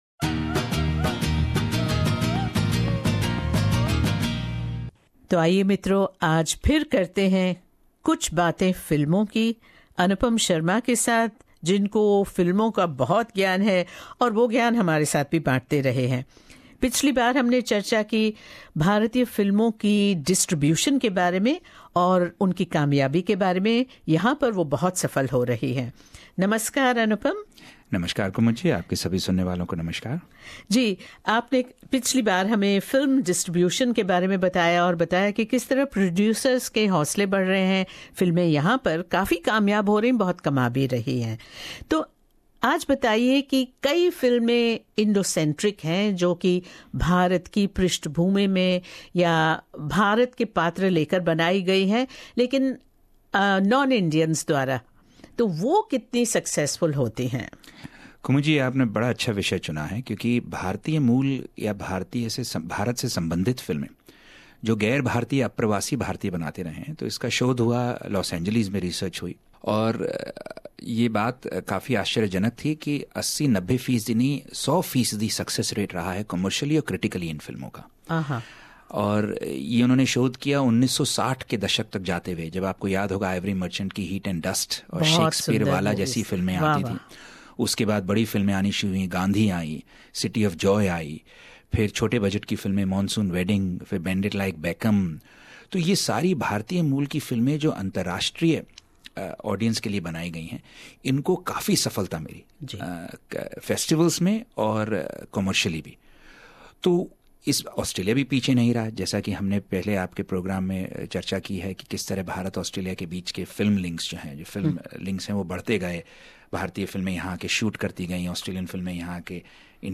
भेंटवार्ता